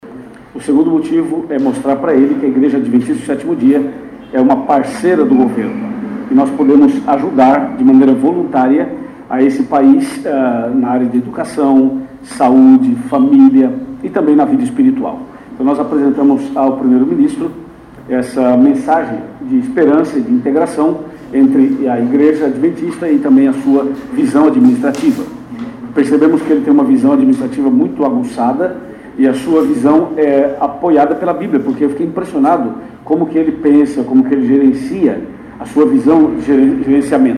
( Pode ouvir pastor brasileiro )